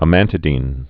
(ə-măntə-dēn)